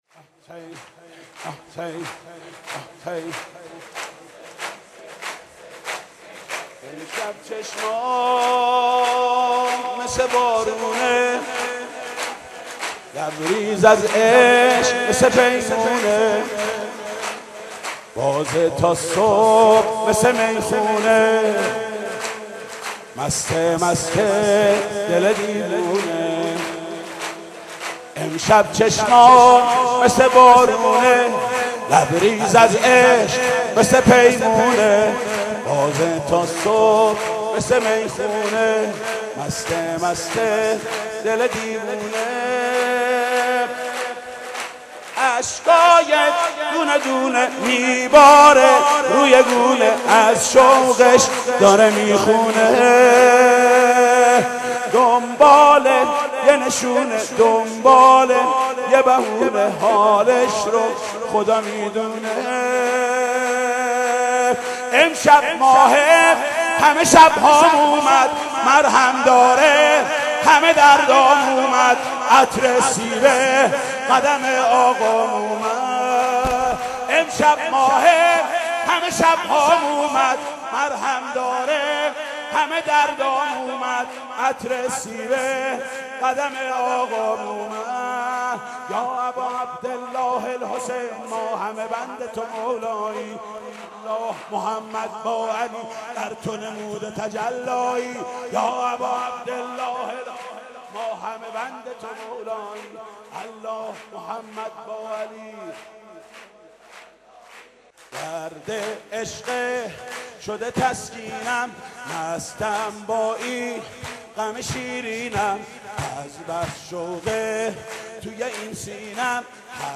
• مداحی